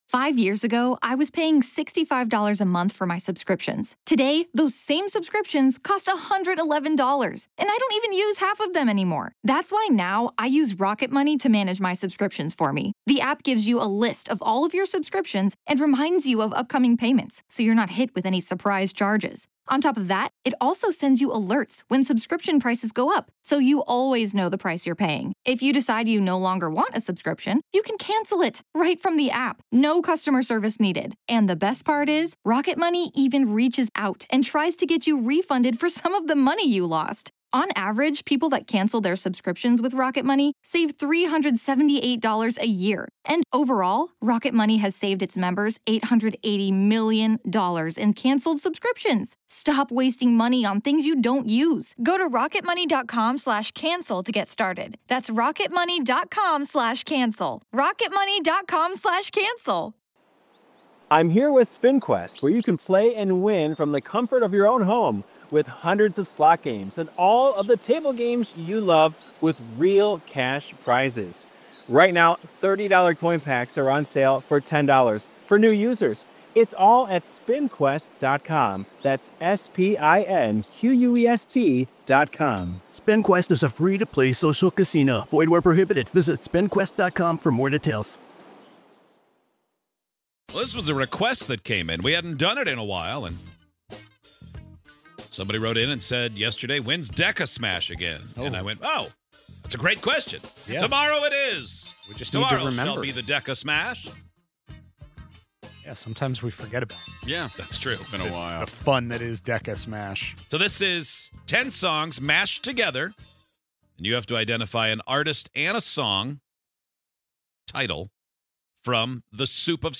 put together a list of ten songs that were one-hit wonders, smashed them all together, and played them at the same time.